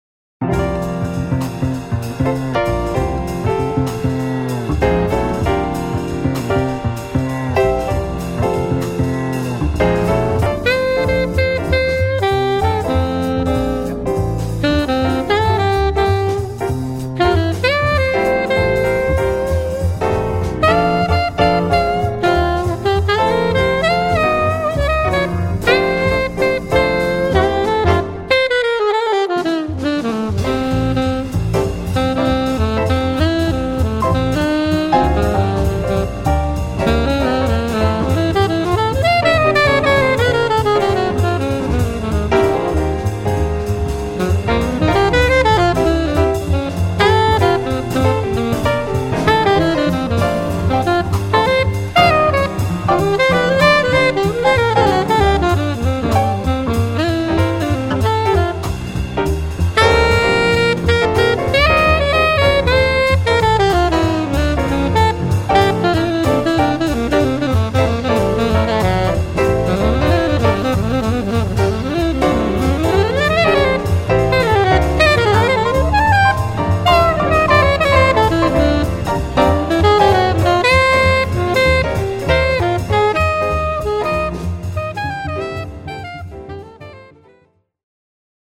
Quartet
(sax / flute, piano / keyboard / voice, bass, drums)
swing